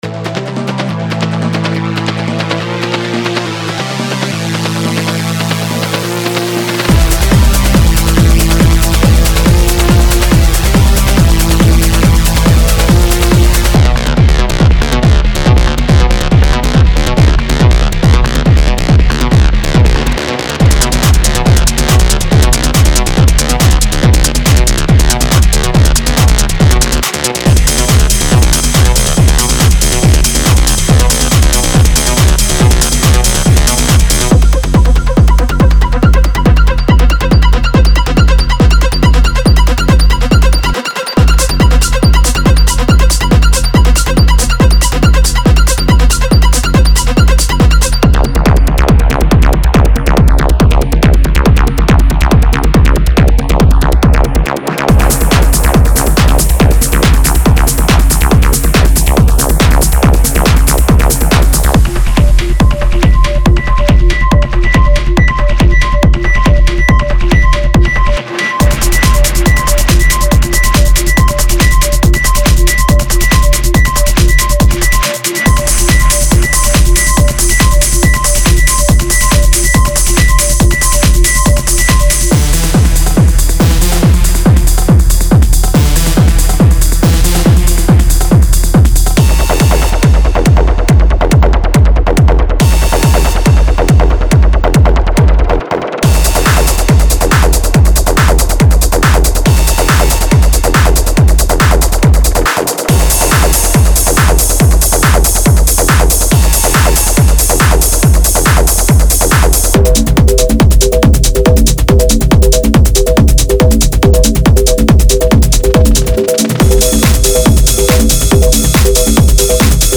デモサウンドはコチラ↓
Genre:Industrial Techno
Tempo/Bpm 140